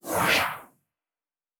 pgs/Assets/Audio/Sci-Fi Sounds/Movement/Synth Whoosh 1_4.wav at master
Synth Whoosh 1_4.wav